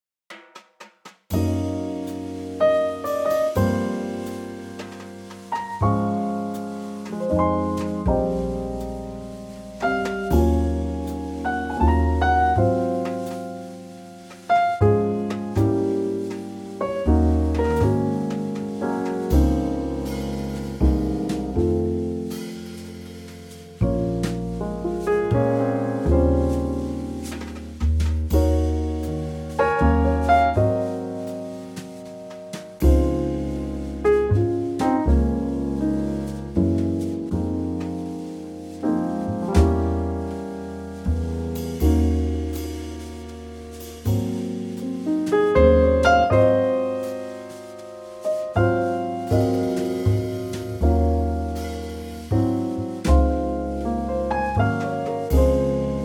Unique Backing Tracks
key - Db - vocal range - Ab to Db
Beautiful old waltz, in a Trio arrangement.